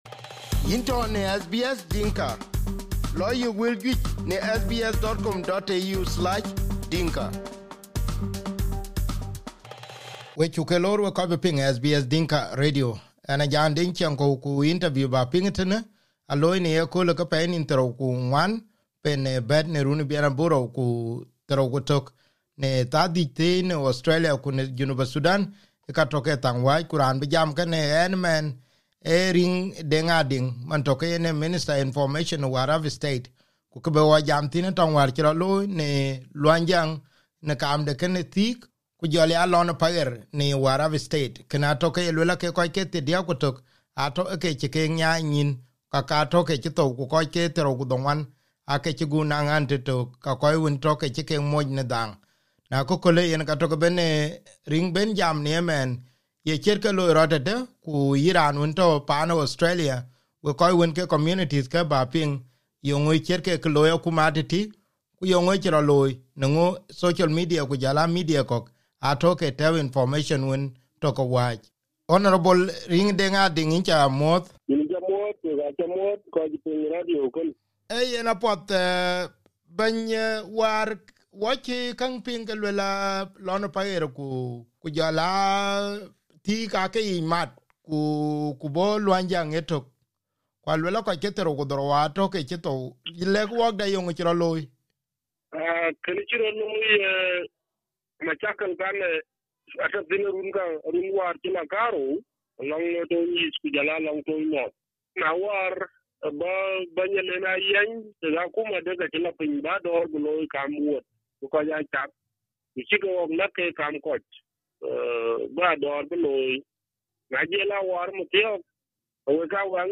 The fighting between the Luanyjang, Lou Paher and Thiik community communities claimed 31 lives from both sides of the conflict. The fighting occurred almost last two weeks ago, and the cause for a fight is cattle rustling, according to the Warrap State Minister of information in an exclusive interview with SBS Dinka.